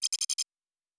pgs/Assets/Audio/Sci-Fi Sounds/Interface/Data 30.wav at master